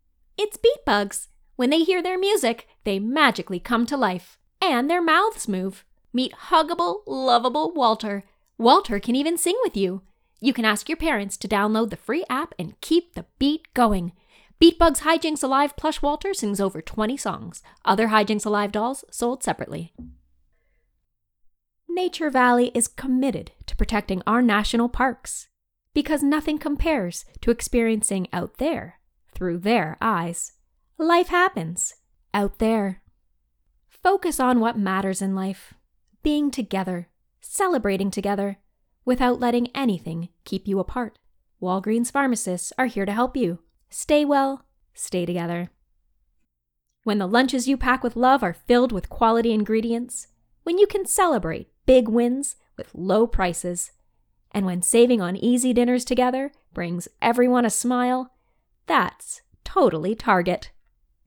Commercial Demo
English - USA and Canada